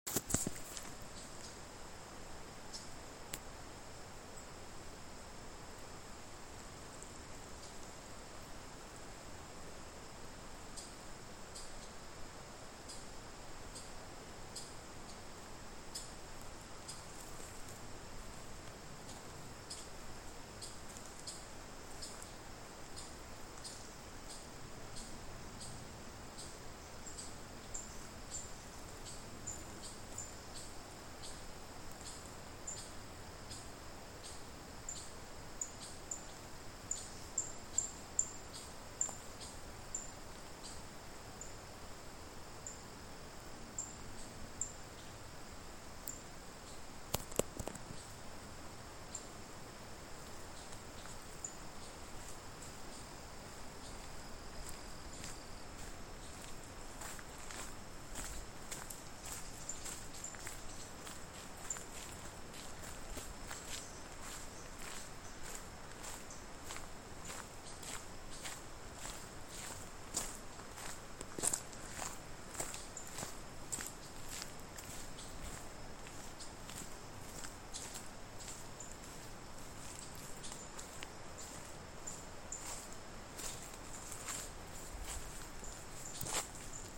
Benteveo Rayado (Myiodynastes maculatus)
Nombre en inglés: Streaked Flycatcher
Localidad o área protegida: Dique El Cadillal
Condición: Silvestre
Certeza: Vocalización Grabada
Benteveo-rayado.mp3